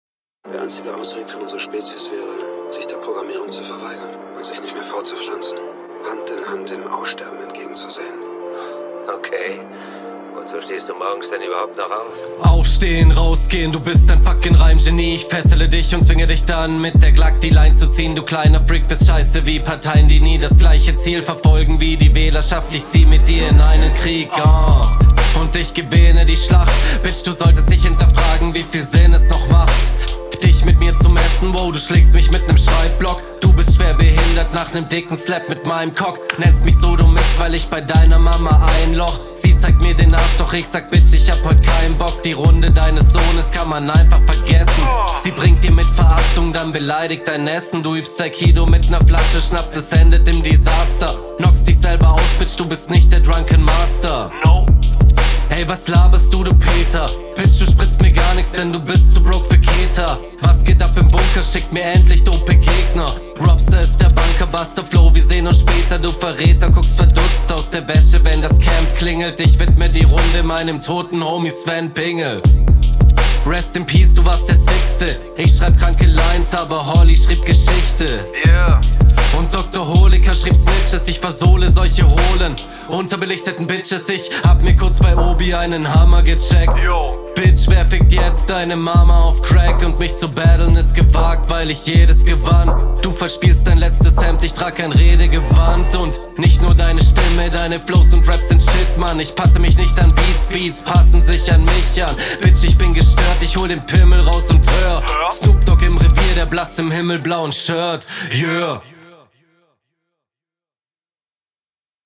Das klingt irgendwie ein bisschen so als wären die Spuren verschoben und der Text ist …